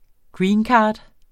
Udtale [ ˈgɹiːnˌkɑːd ]